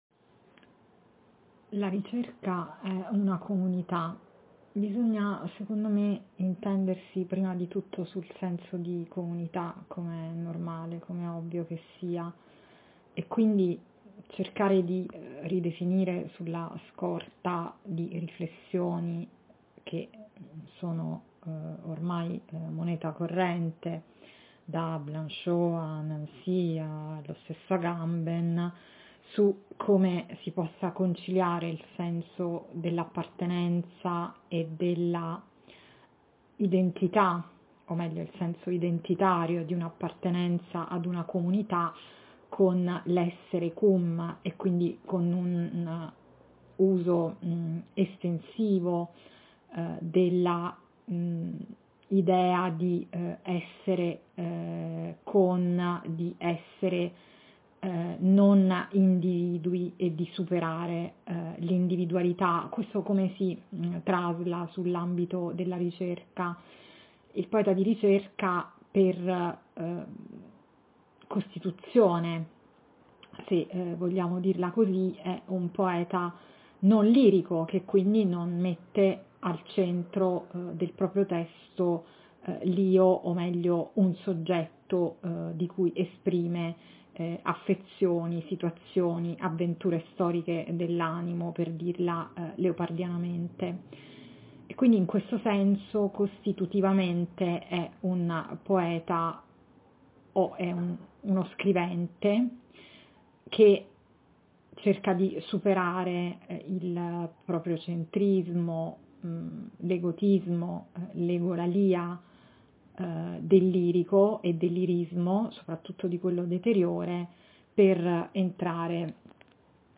Come in occasione di uno dei due incontri milanesi del 2023, anche stavolta  una microintervista composta da 4 domande fisse viene proposta a diversi interlocutori del (o partecipanti al) quinto momento, 2025, di Esiste la ricerca, dedicato al tema delle comunità e dei progetti letterari.
È lasciata la massima libertà nel rispondere (o non rispondere) via mp3, ma è richiesta una serie di condizioni in linea con la natura estemporanea di ELR: i vocali non devono essere ‘preparati’, né letti, né (poi) editati, né in sostanza pensati come elementi di un’esposizione calligrafica / accademica, bensì pronunciati al microfono e registrati così come nascono.